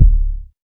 KICK.37.NEPT.wav